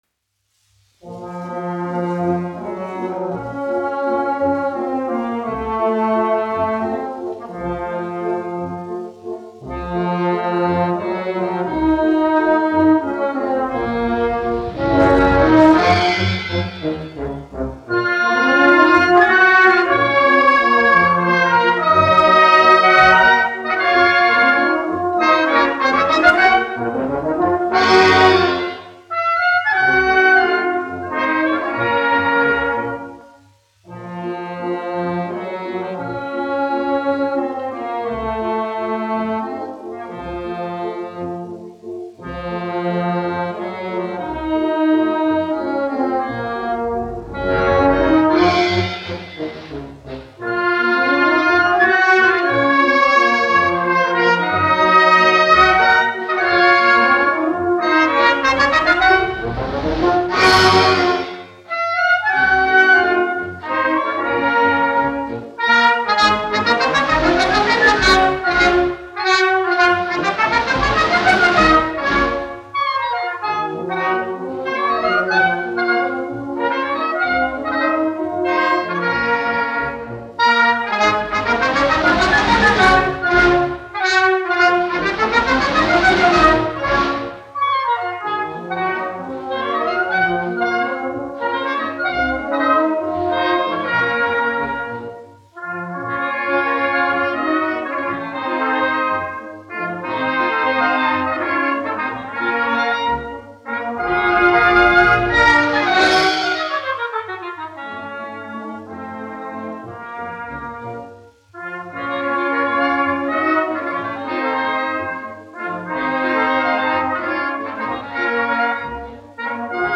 1 skpl. : analogs, 78 apgr/min, mono ; 25 cm
Valši
Pūtēju orķestra mūzika
Latvijas vēsturiskie šellaka skaņuplašu ieraksti (Kolekcija)